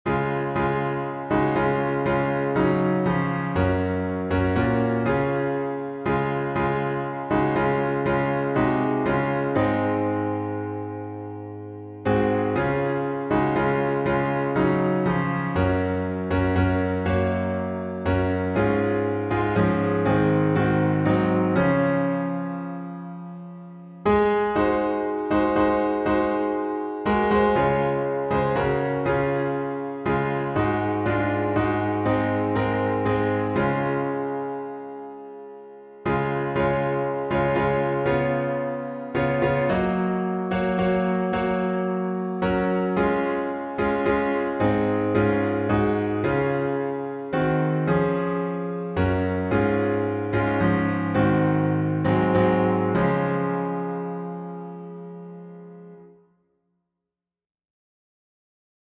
Key signature: D flat major (5 flats) Time signature: 6/8
He_Hideth_My_Soul_Dflat.mp3